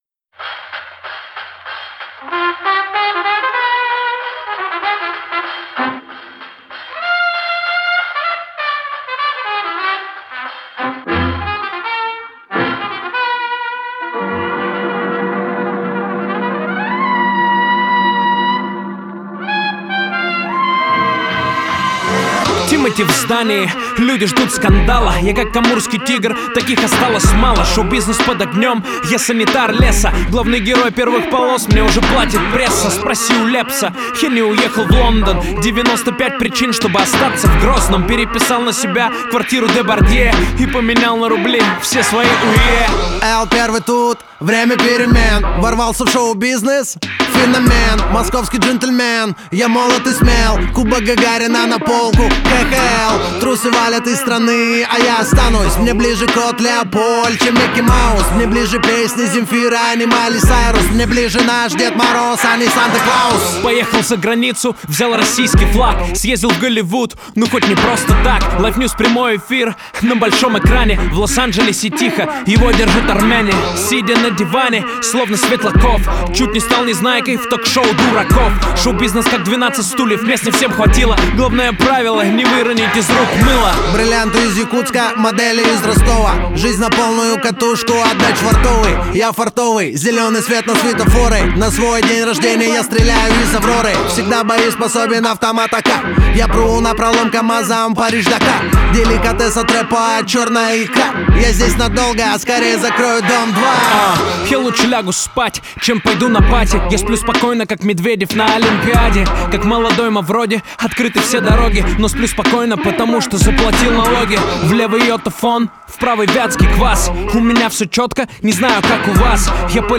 Категория: Русский рэп 2016